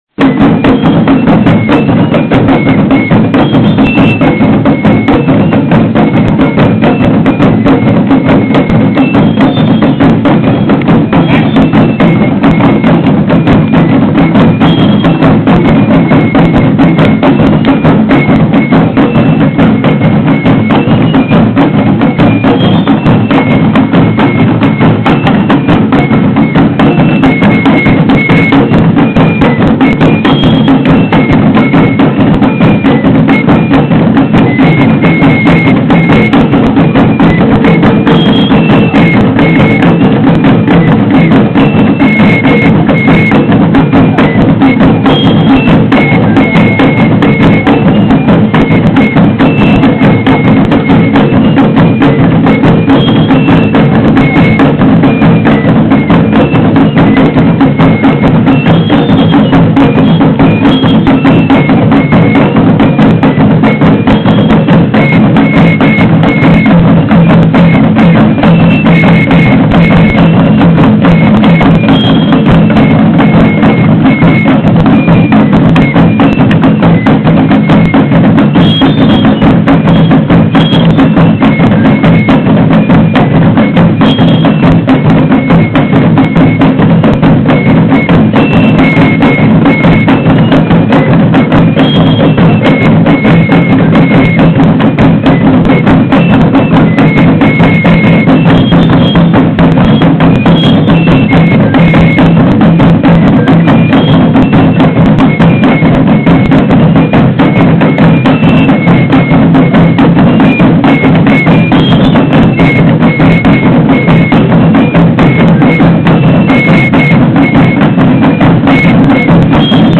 Durante la romería y festividades como la feria de San Caralampio en Comitán de Domínguez, Chiapas, se reunen los jefes de las iglesias de diversas comunidades - en su mayoría tojolabales-, a tocar: El tamborcito, una mezcla de tambores y pitos que acompañan su peregrinar en espera de la lluvia.
En esta ocasión, el punto de reunión fue el atrio de la Iglesia de San Caralampio en Comitán y su destino la iglesia del Padre Eterno en La Trinitaria, Chiapas.
28 de diciembre de 2006 Lugar: Atrio de la Iglesia de San Caralampio en Comitán, Chiapas; Mexico. Equipo: Pendrive Marca Markvision.